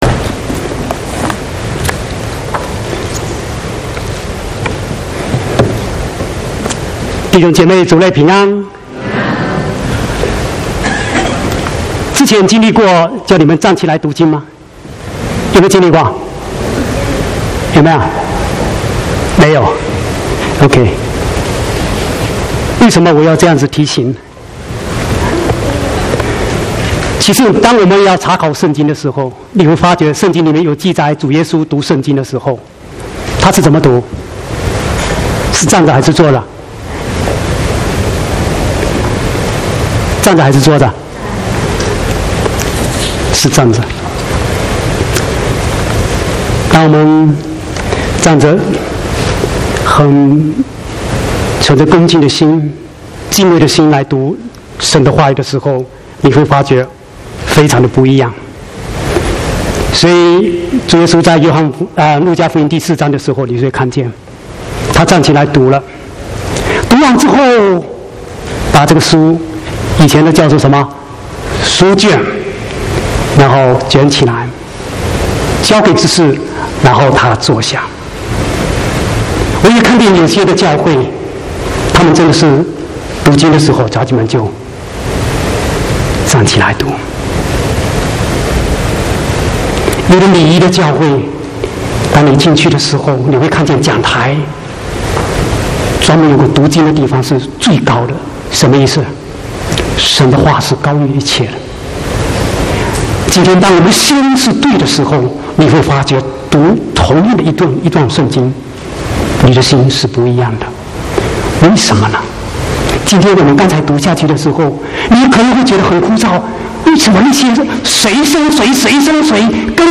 10/4/2016國語堂講道